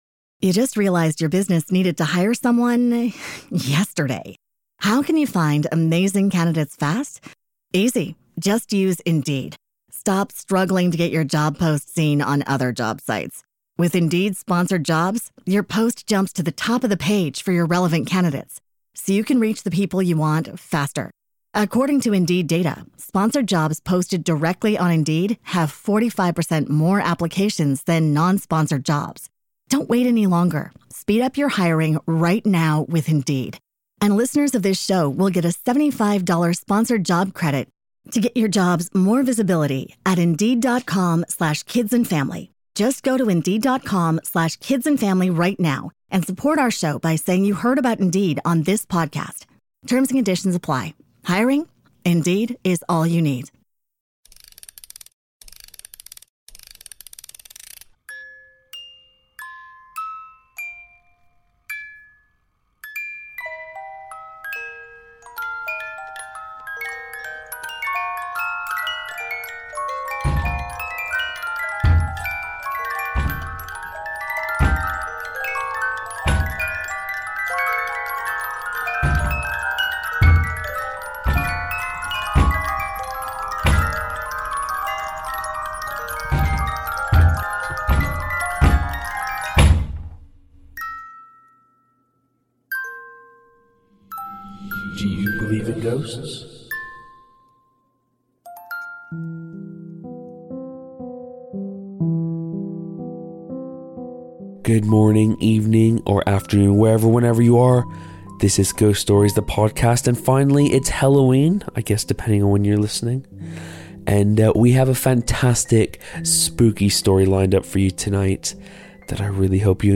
Make sure to vote for if you think this story is based in fact or a pure work of fiction! Music (in order of appearance):